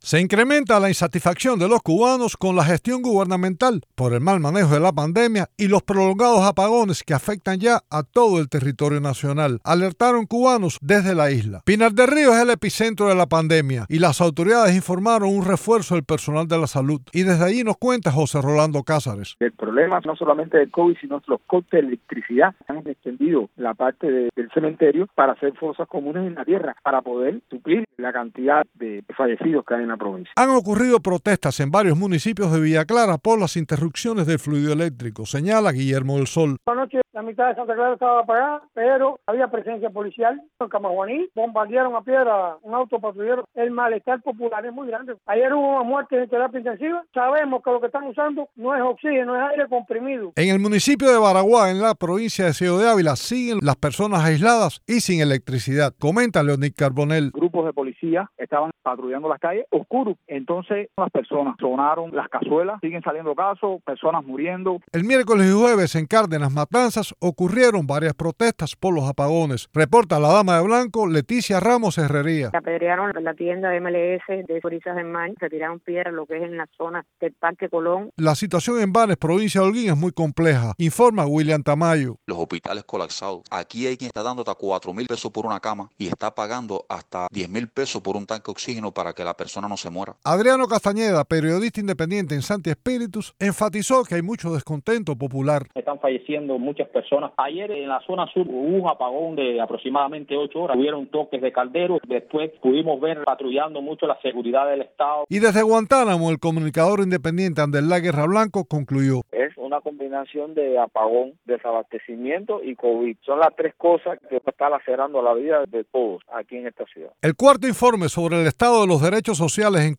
Varias personas hablaron a Radio Televisión Martí sobre pequeños incidentes de protestas que se están dando en distintas regiones de Cuba a pesar del férreo despliegue policial.
Reportaje